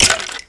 Minecraft Version Minecraft Version latest Latest Release | Latest Snapshot latest / assets / minecraft / sounds / mob / skeleton / hurt3.ogg Compare With Compare With Latest Release | Latest Snapshot
hurt3.ogg